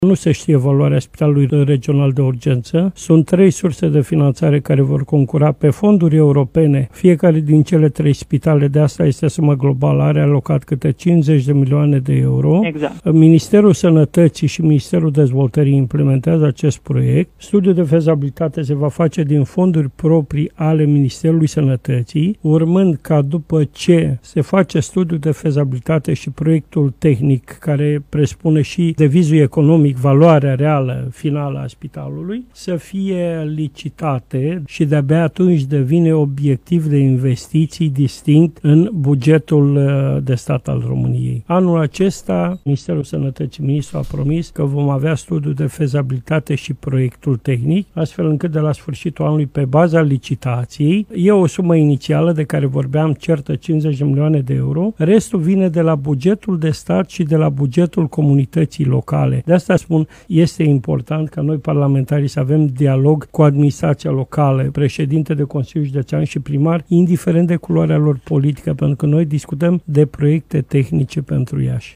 Prezent în emisiunea Imperativ, de la Radio Iași, deputatul PMP, Petru Movilă, a explicat care sunt etapele construcției Spitalului Regional de la Iași și cum vor putea fi alocate fondurile necesare:
28-ian-Petru-Movila-spital.mp3